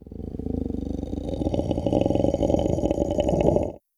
Monster Roars
20. Sleeping Dragon Growl.wav